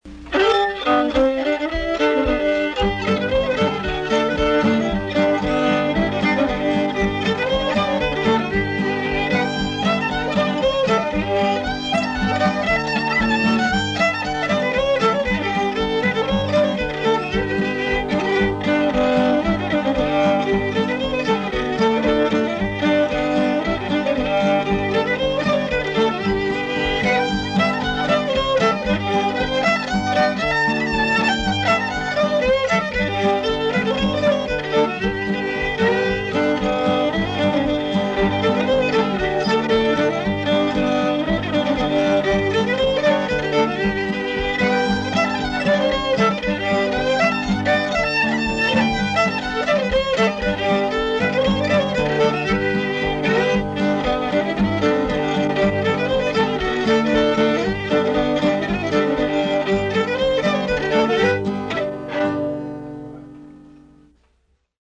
Played in G, in standard fiddle tuning GDAE.
fiddle